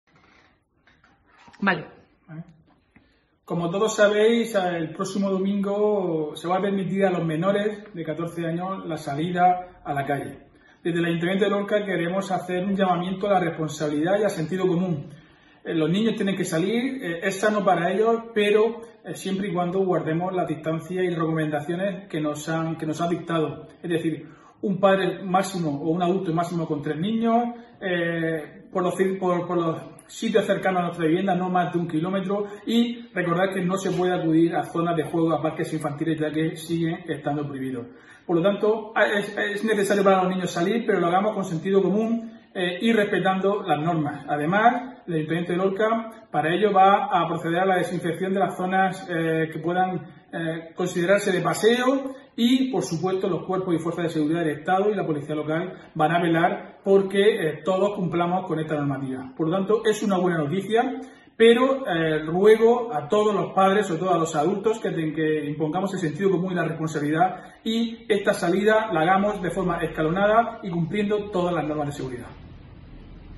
Diego José Mateos, alcalde de Lorca sobre salida de niños a la calle